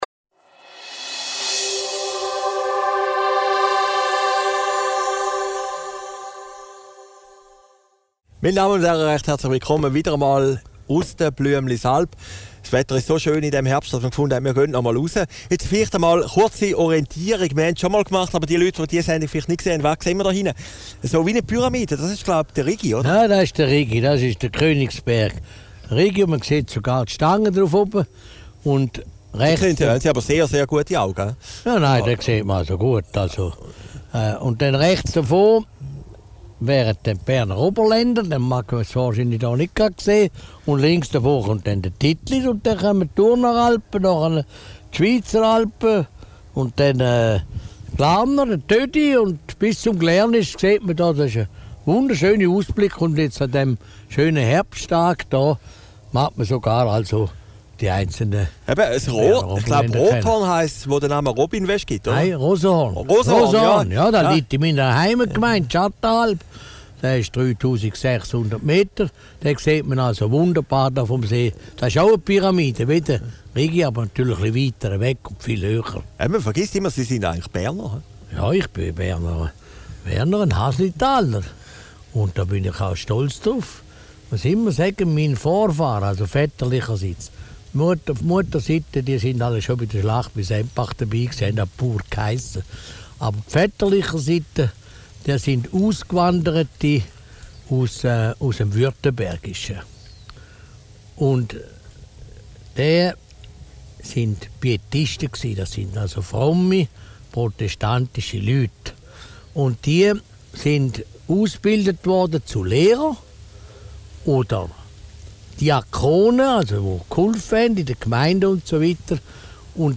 Video downloaden MP3 downloaden Christoph Blocher über seinen Grossvater in der Fremdenlegion, schlechten Stil und die Macht der Verwaltung Aufgezeichnet im Rest. Blüemlisalp, Herrliberg im Oktober 2013